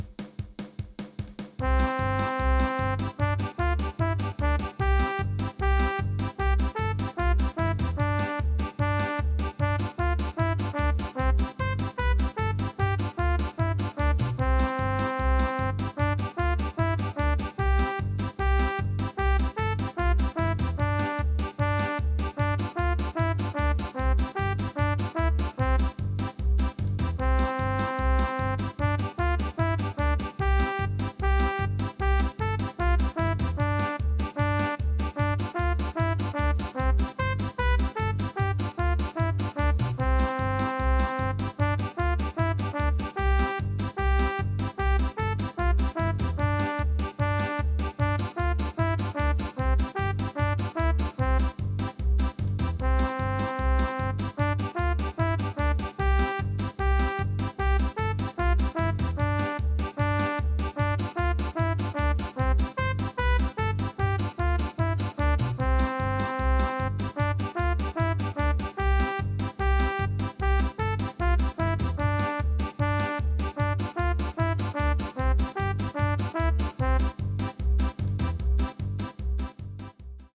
Poco convencido compré un teclado usado y el libro Keyboard Starter 1.